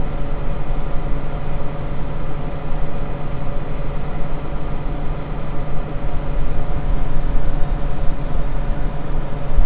Ich höre seit einiger Zeit (ich kann leider nicht rekonstruieren, wann/warum genau es hergekommen ist) sog. "whining", also ein hochfrequentes Fiepen (vermutlich) des CPUs (oder Netzteil?) auf Boxen, Kopfhörern, usw.
Es hängt definitiv mit der Taktung des CPU zusammen, ich kann in meinem Betriebssystem zwischen "powersafe" (niedrig), "on demand" (dynamisch) und "performance" (dauerhaft hoch) umschalten und dementsprechend lauter wird auch dieses Geräusch.